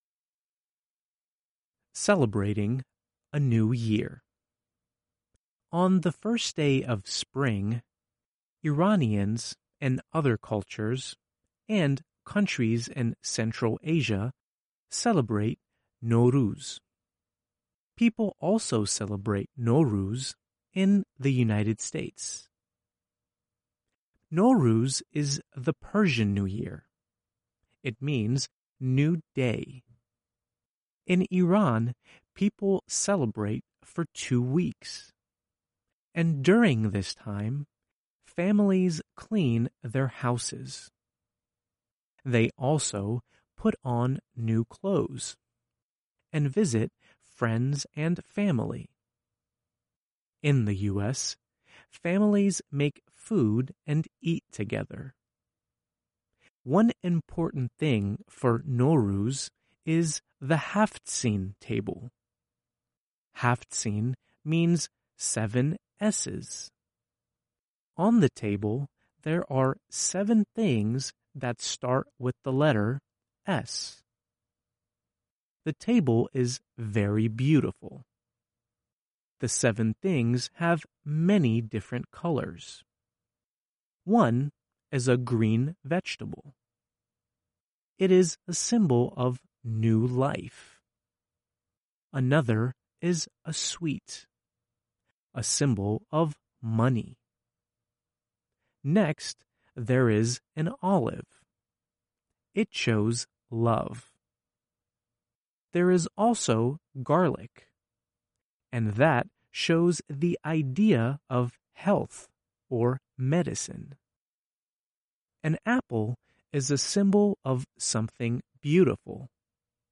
Reading: Celebrating a New Year